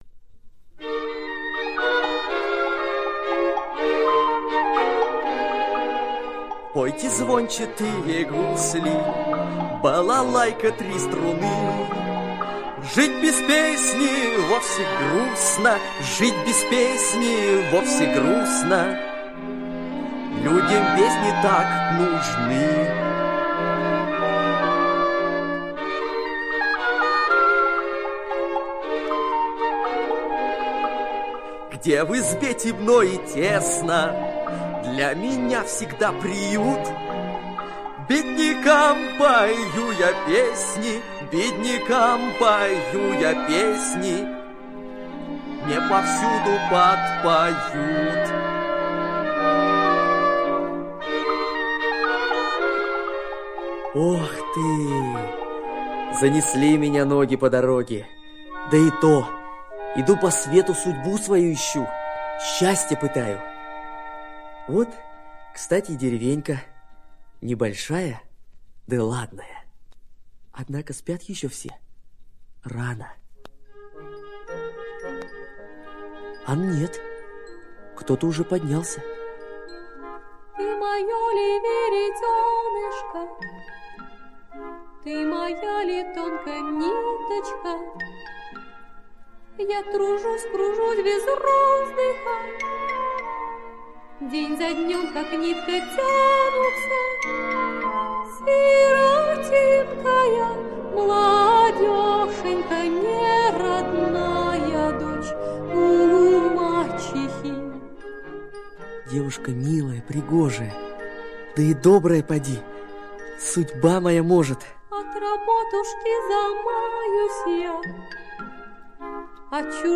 Машенькина свирель - аудиосказка Садовского - слушать онлайн